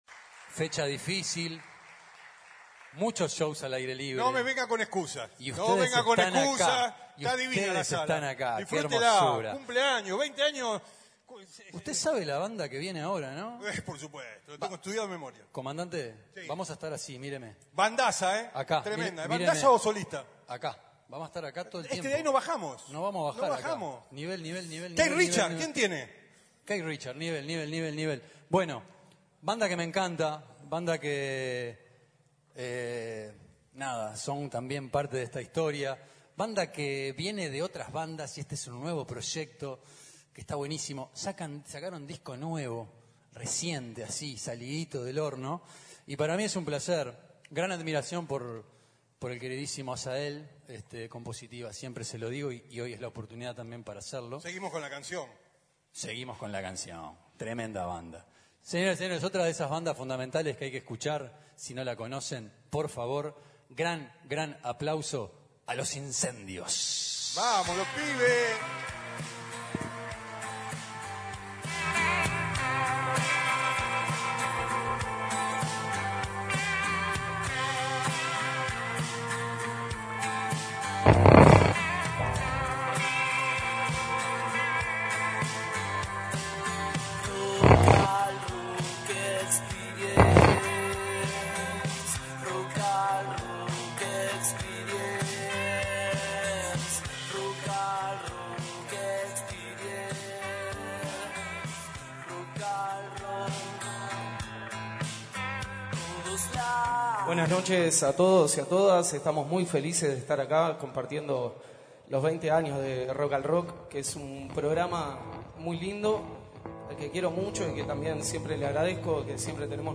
El pasado 20 de diciembre se llevó a cabo el festejo por los 20 años del programa en la Sala Zitarrosa